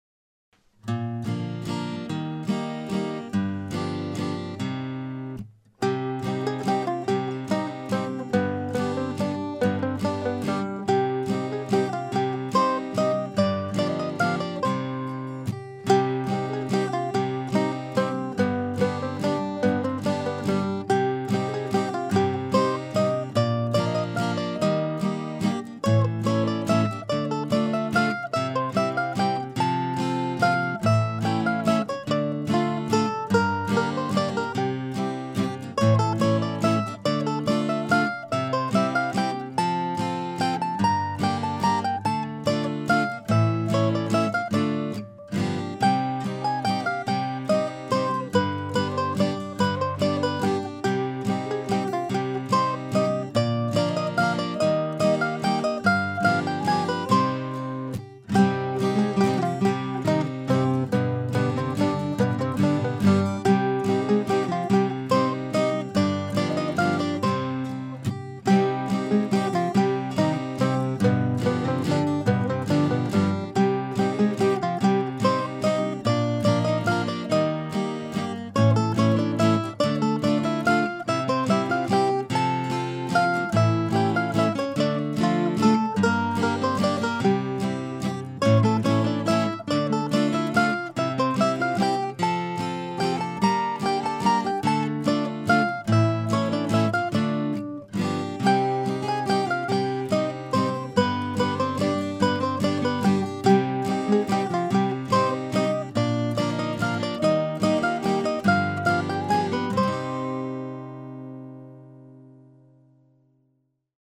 Until tonight it was just called "June 26, 2011 Waltz".